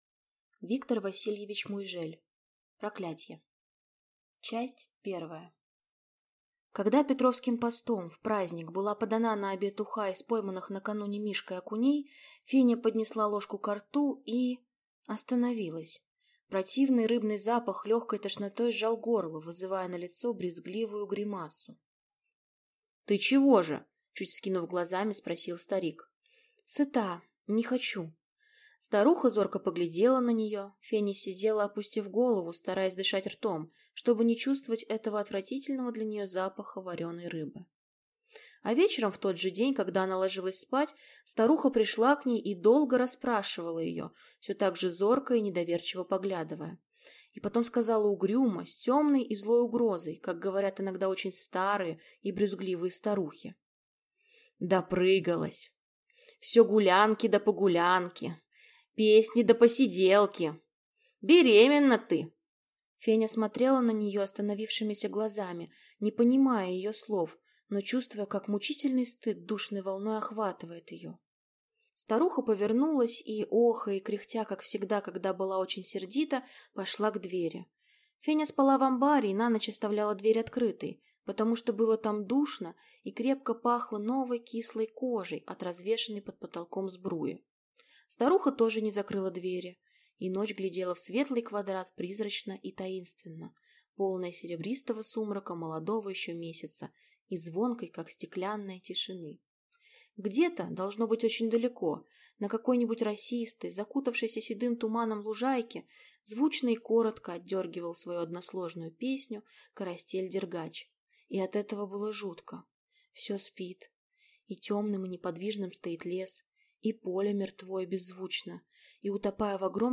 Аудиокнига Проклятие | Библиотека аудиокниг
Прослушать и бесплатно скачать фрагмент аудиокниги